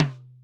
Index of /kb6/Akai_XR-20/Tom